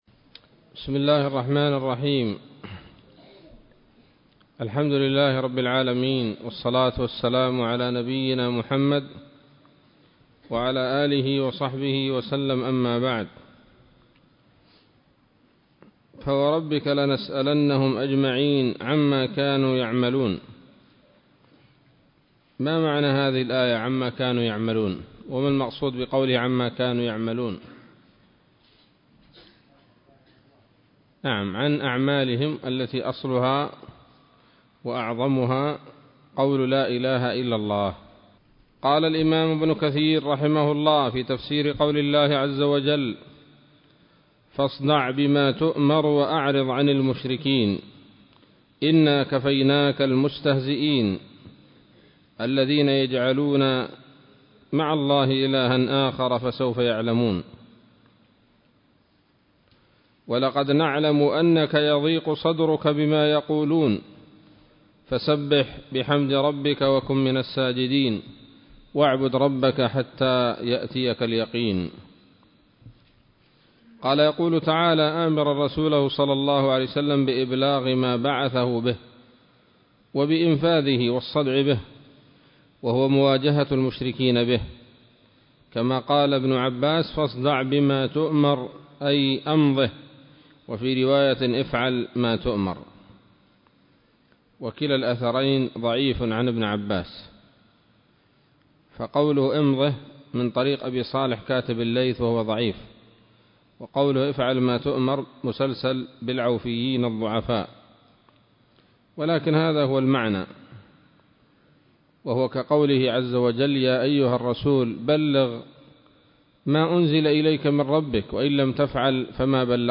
الدرس الحادي عشر من سورة الحجر من تفسير ابن كثير رحمه الله تعالى